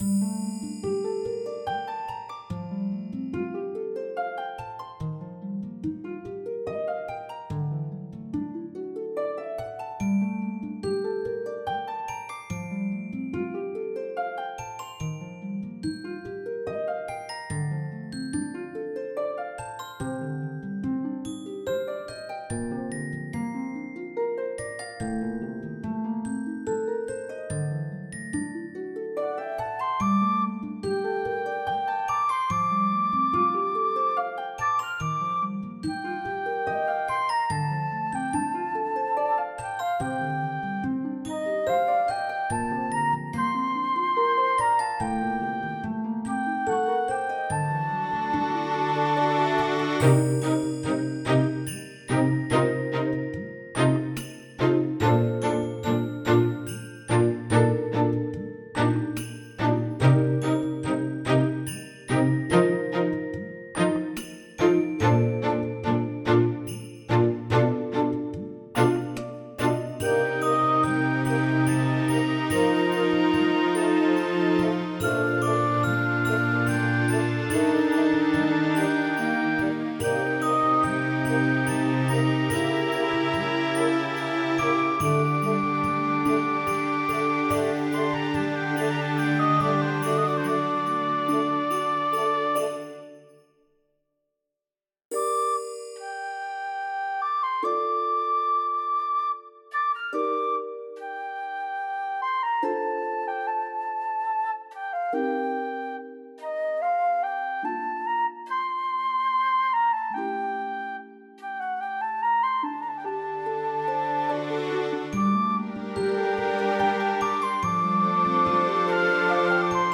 フリーBGM素材- 星空の下で物語を詠んだり、そんな感じ。